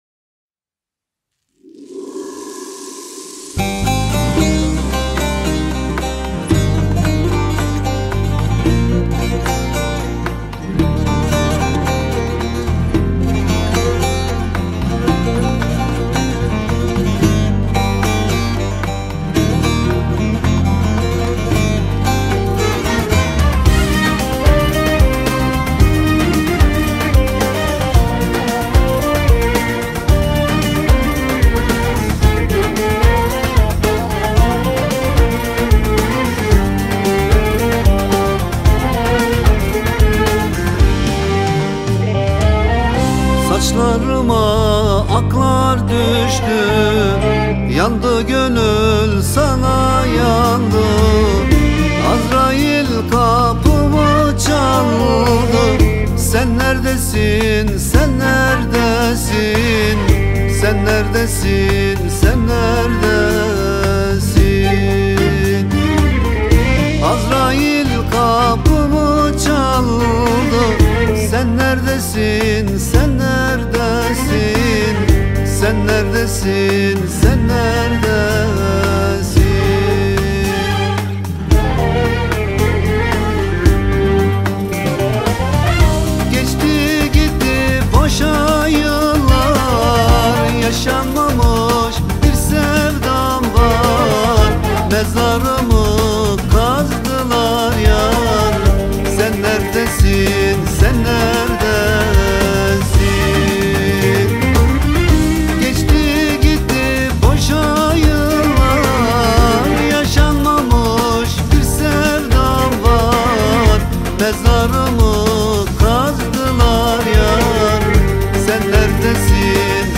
Турецкая песня